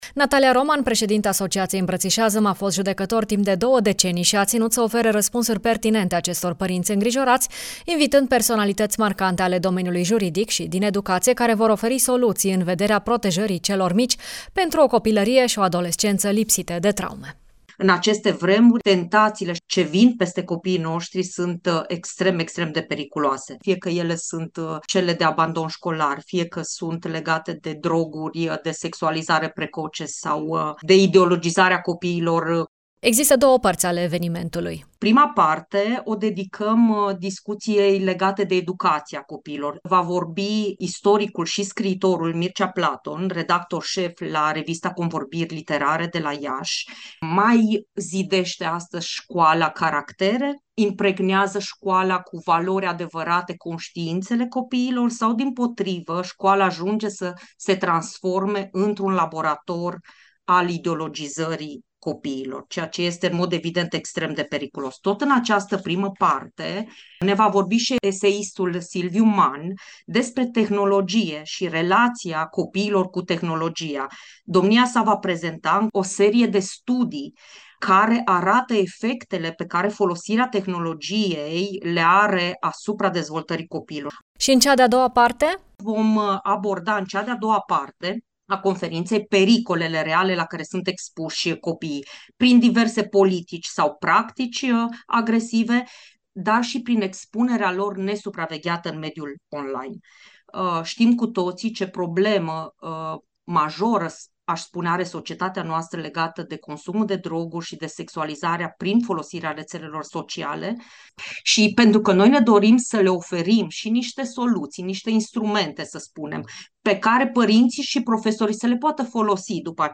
a stat de vorbă cu organizatorii conferinței